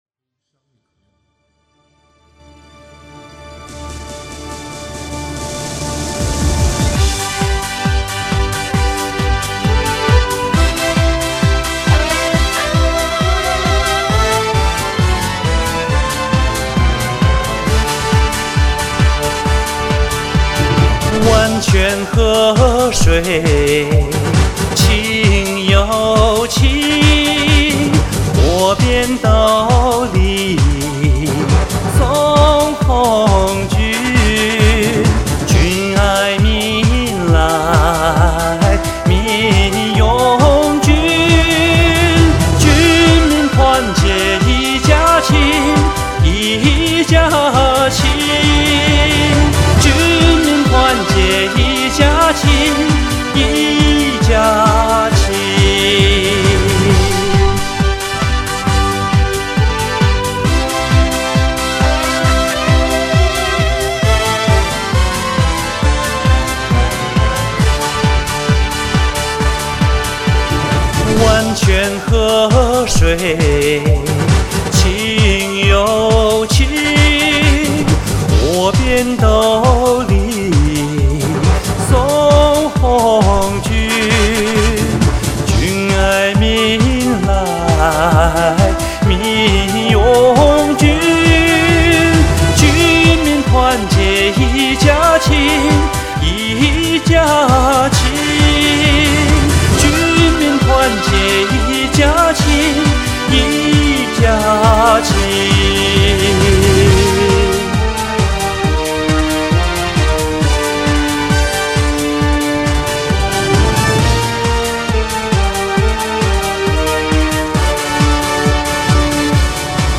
【胜利70年】活动即将结束，再来一首欢快的歌曲献给大家，祝活动圆满成功，祝各位开心愉快！